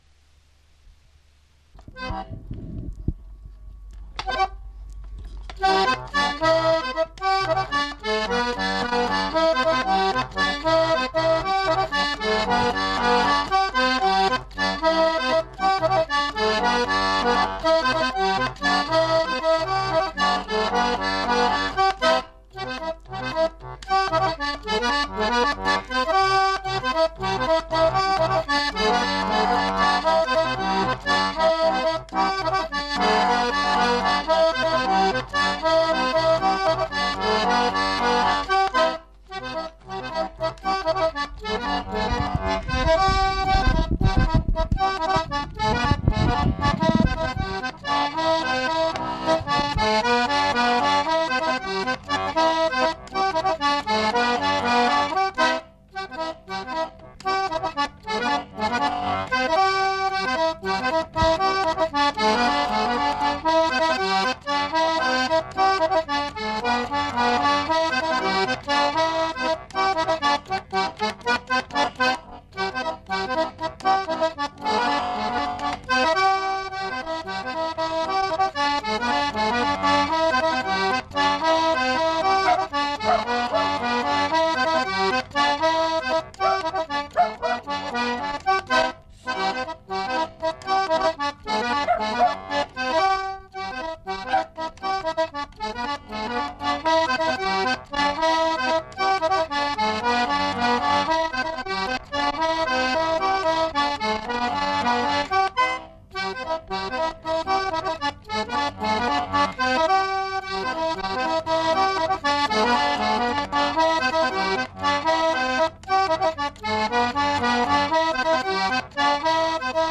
Aire culturelle : Savès
Genre : morceau instrumental
Instrument de musique : accordéon diatonique
Danse : gigue
Notes consultables : Problème de changement de vitesse en fin de séquence.